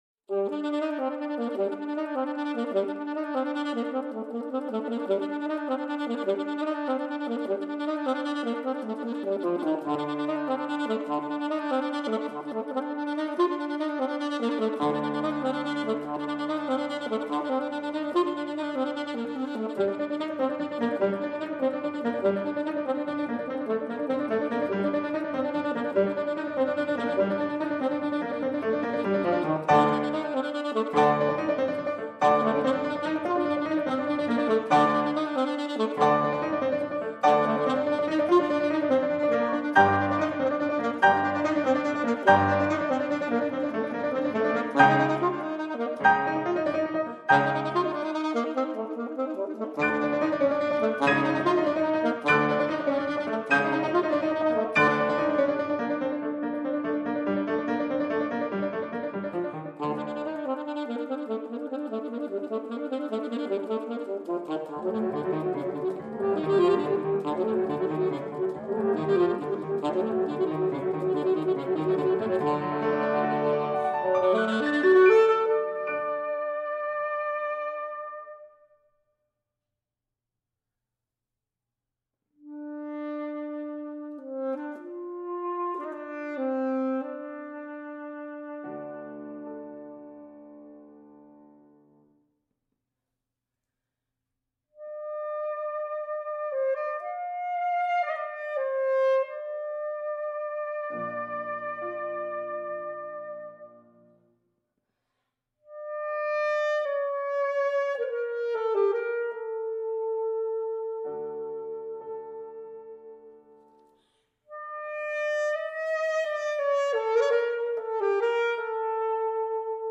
saxophone
piano
Through Spanish, Hellenic, and Arab sonorities and across different eras, Duo Aster reveals the beauty of the saxophone and the depth of the piano.